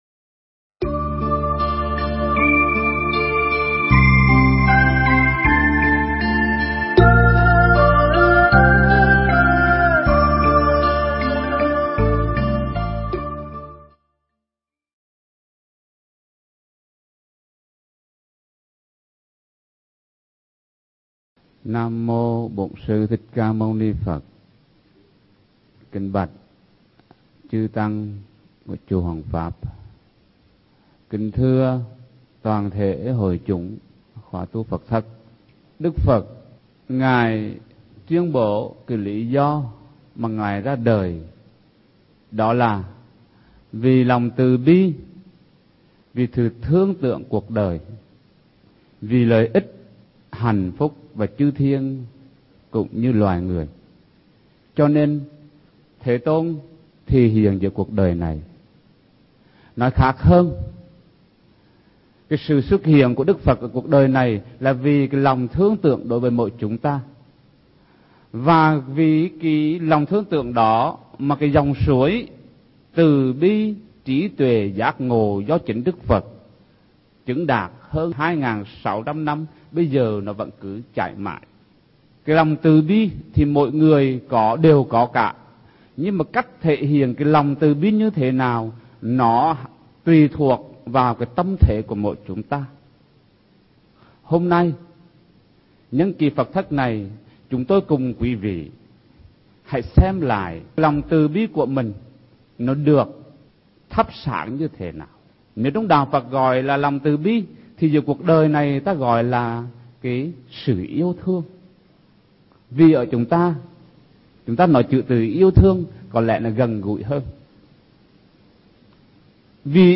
Thuyết pháp online